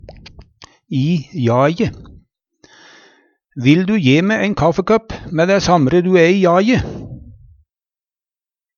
DIALEKTORD PÅ NORMERT NORSK i jaje i farten, vera på føtene, vera i bevegelse Eksempel på bruk Vil di je me ein kaffekøpp mæ ræ samre du æ i jaje?